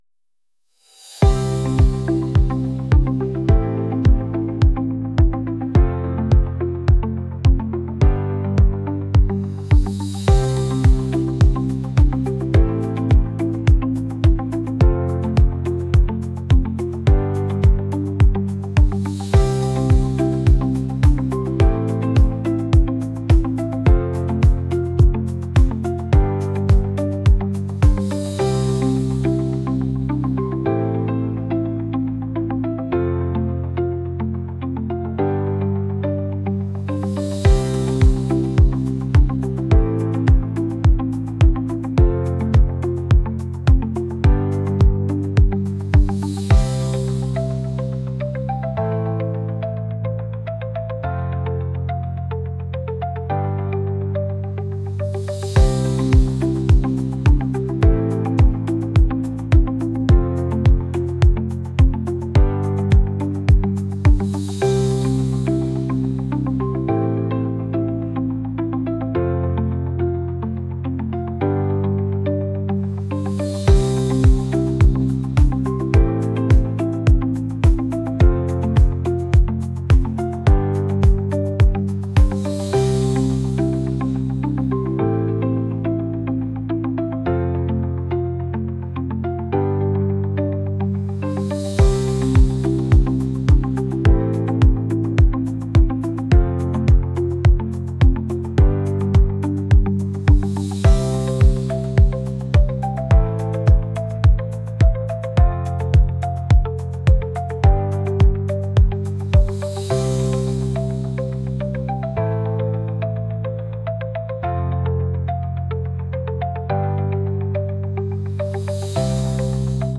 acoustic | pop | cinematic